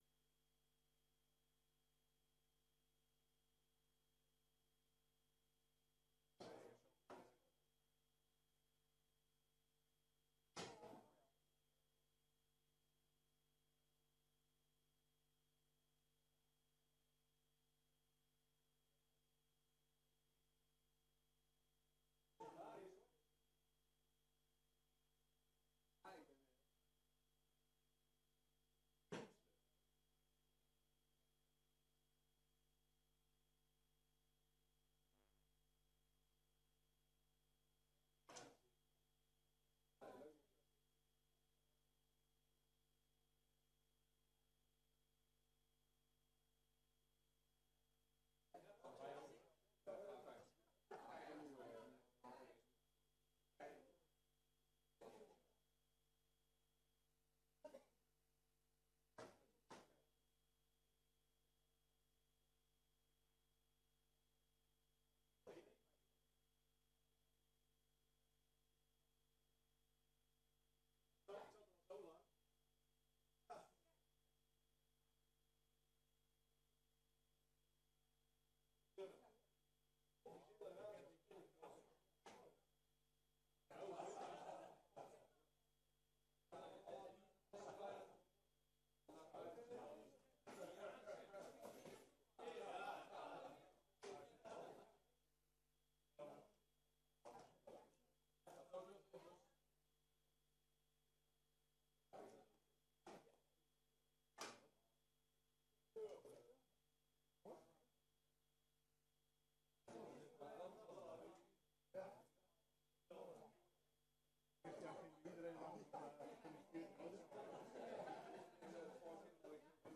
Raadsvergadering
Locatie: Raadzaal